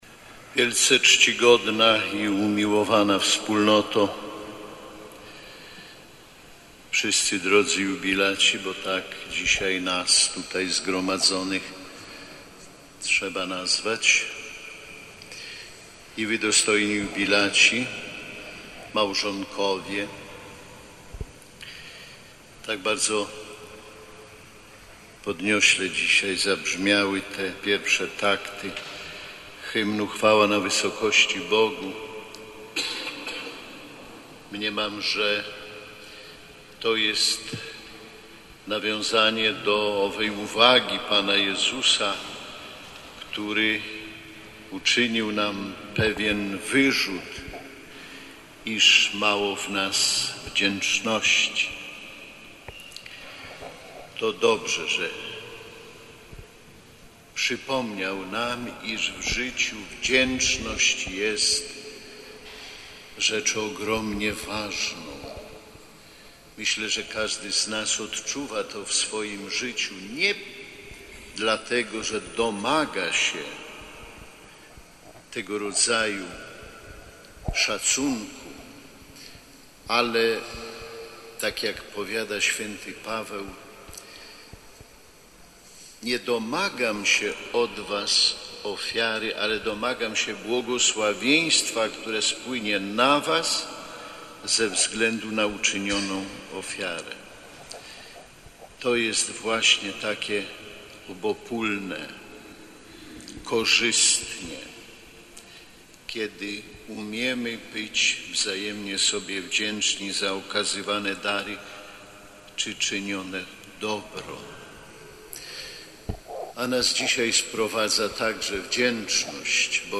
Ordynariusz Warszawsko-Praski uczestniczył w uroczystej Mszy Świętej w parafii Najczystszego Serca Maryi na warszawskim Grochowie z okazji 200-lecia urodzin arcybiskupa Zygmunta Szczęsnego Felińskiego.